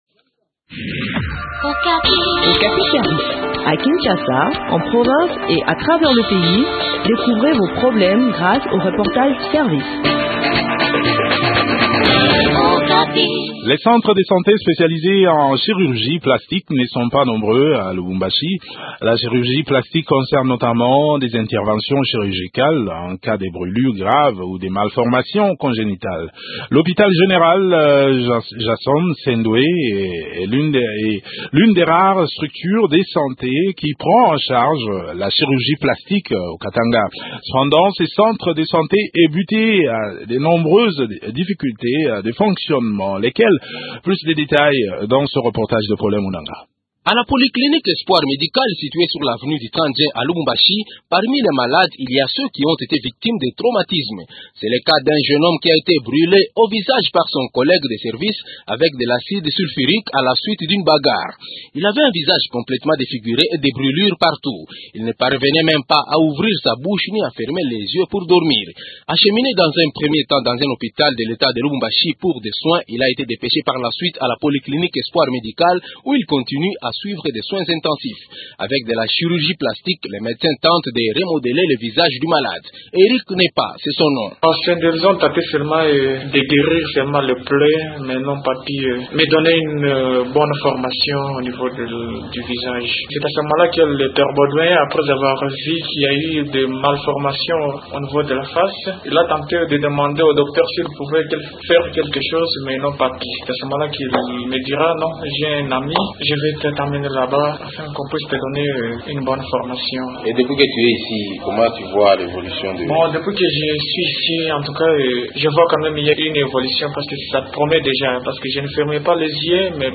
Le point de la situation sur le terrain dans cet entretien